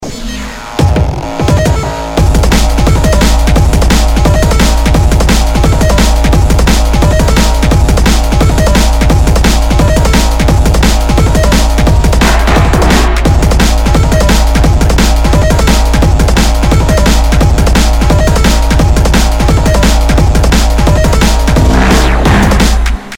Drum'n'bass